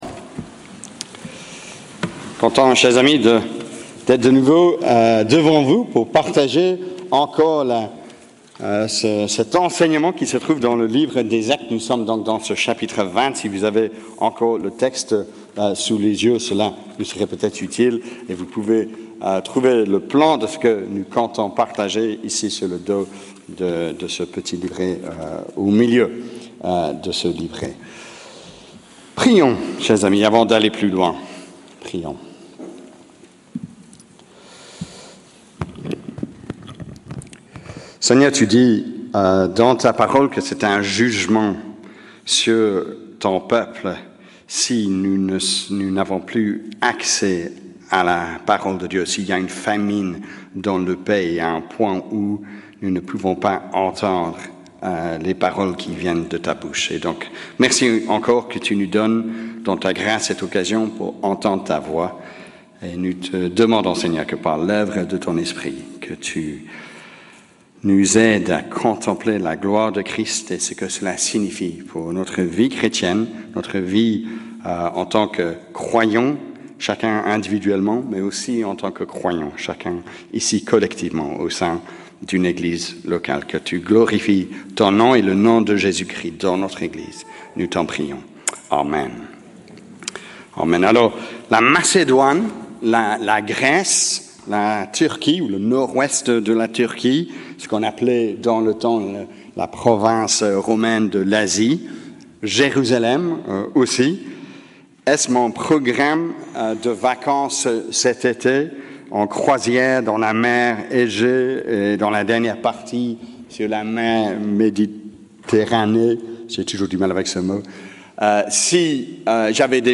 Predication-June-1st-2025_audio.mp3